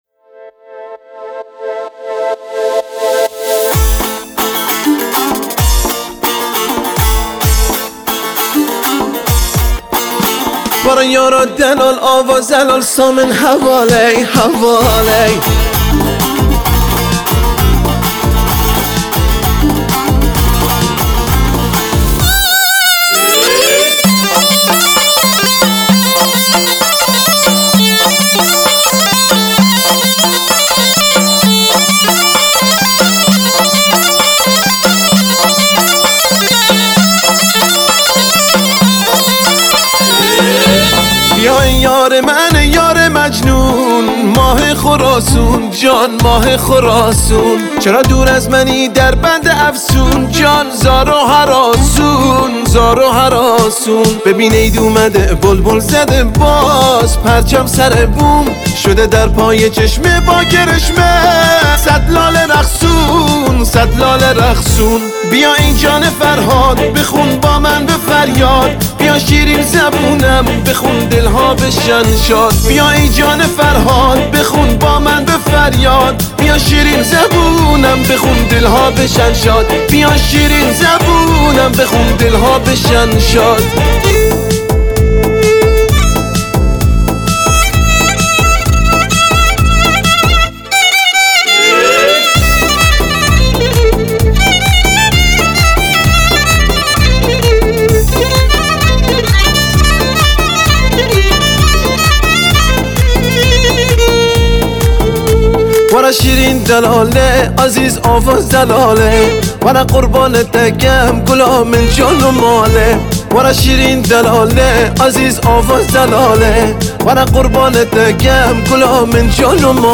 موزیک کرمانجی